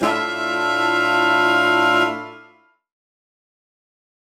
UC_HornSwellAlt_Bsus4min6.wav